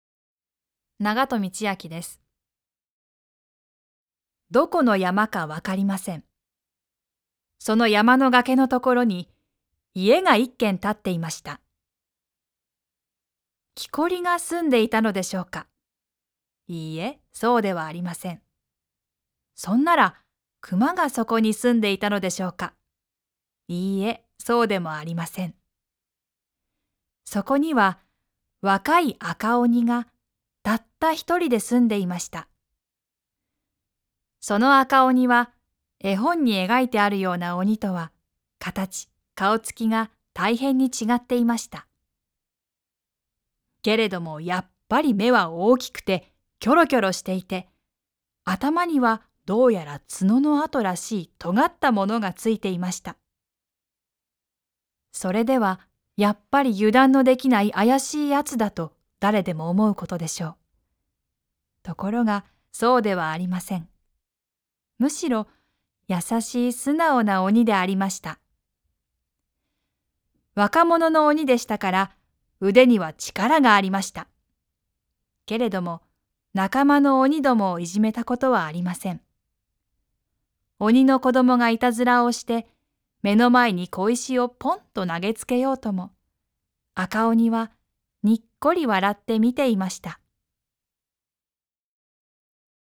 朗読『ないた赤おに』